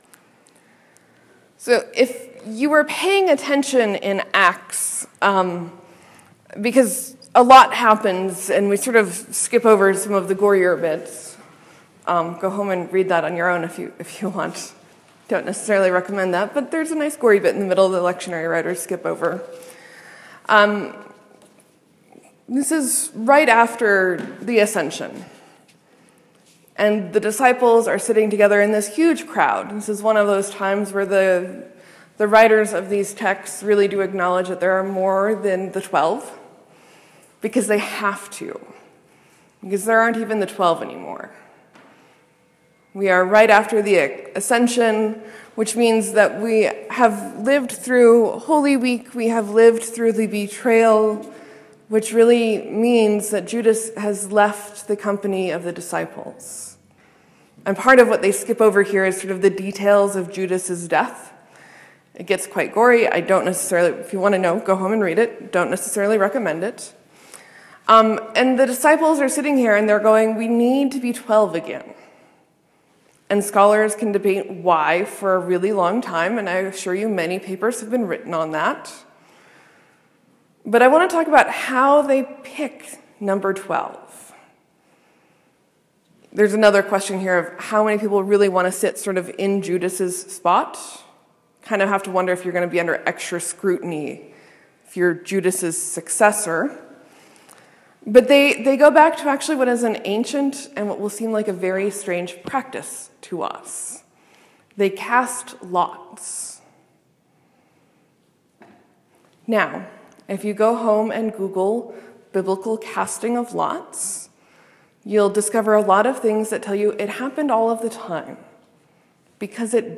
Sermon: The disciples fill Judas’s place among them.